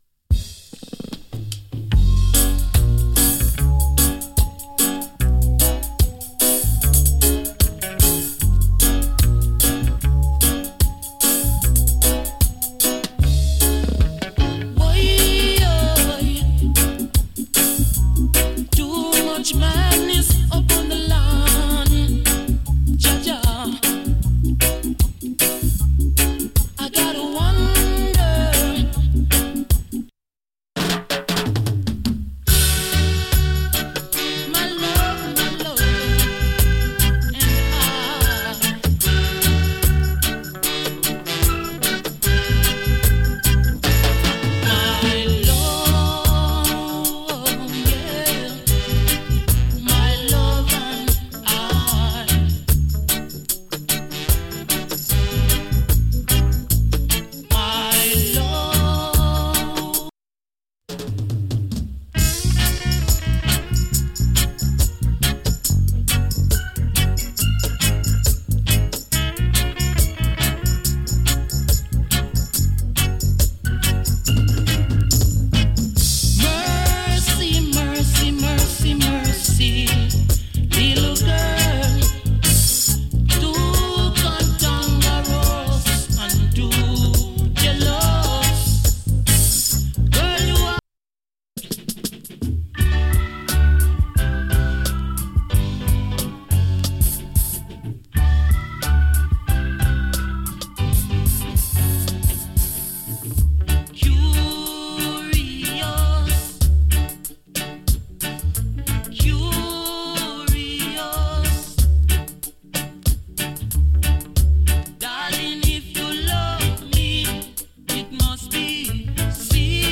極わずかにチリ、パチノイズ有り。
ROOTS REGGAE ALBUM